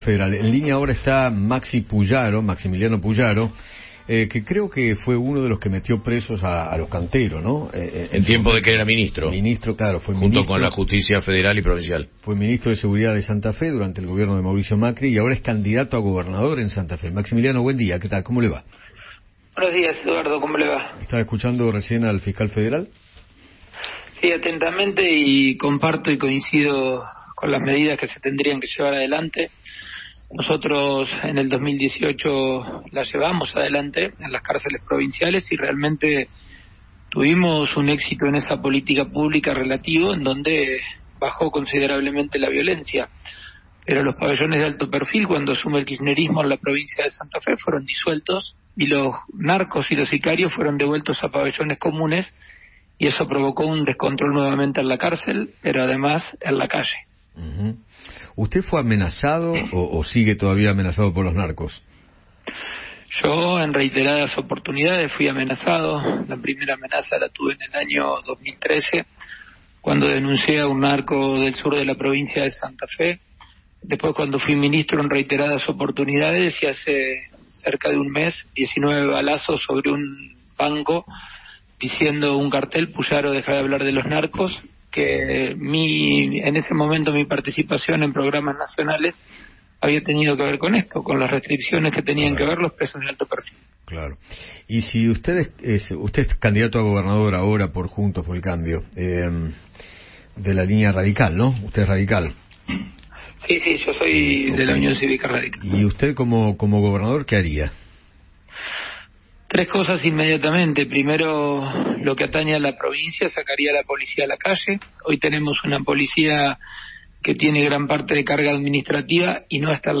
Maximiliano Pullaro, candidato a gobernador de Santa Fe, dialogó con Eduardo Feinmann sobre sus propuestas y se refirió a la reforma de las leyes de Ejecución penal.